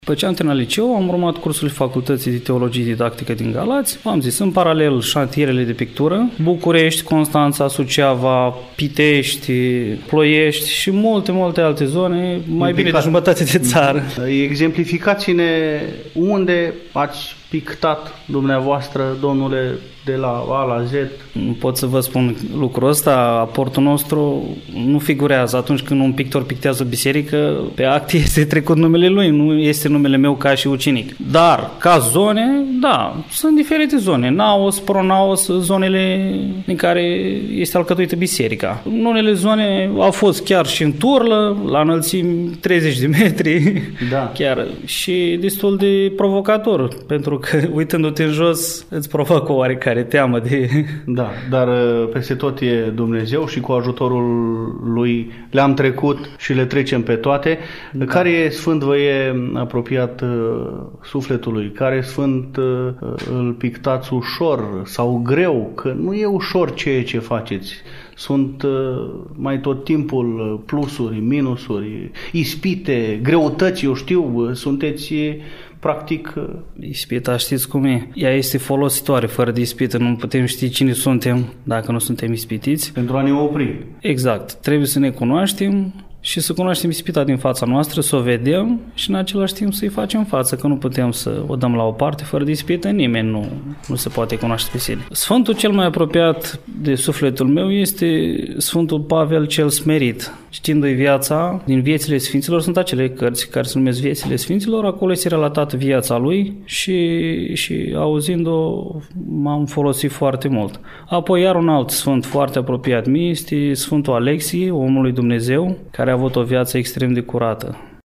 Mare este minunea lui Dumnezeu, întrucât omul nu a fost dăruit doar cu harul vederii spirituale în pictură, ci a fost înzestrat și cu glas îngeresc, pentru a transmite mesajul creștinilor, într-un glas divin, direct îngerilor și lui Dumnezeu Atotputernic.
cântăreţ bisericesc şi pictor